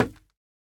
Minecraft Version Minecraft Version latest Latest Release | Latest Snapshot latest / assets / minecraft / sounds / block / bamboo_wood / break1.ogg Compare With Compare With Latest Release | Latest Snapshot